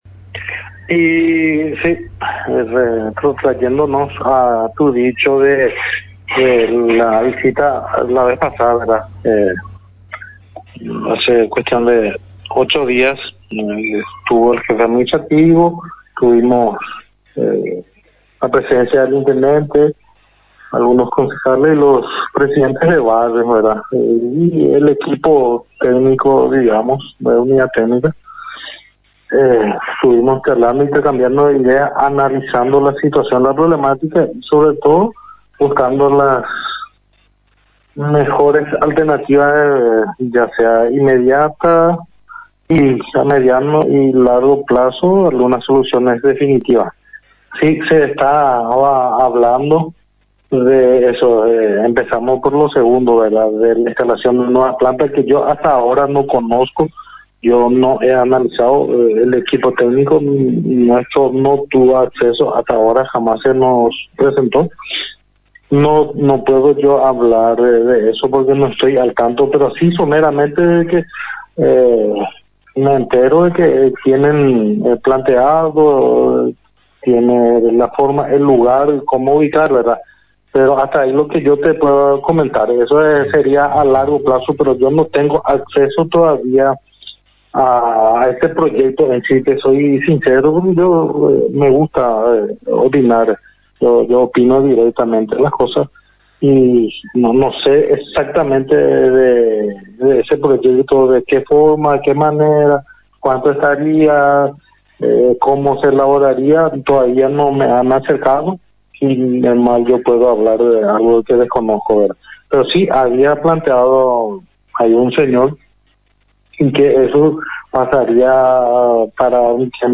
En contacto con Misión FM